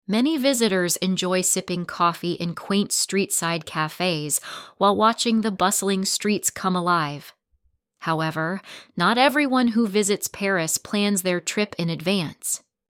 female_usa
excited
AI Voice Over Assistant
This ai agent will read your text you provide in the style and in the voice you choose.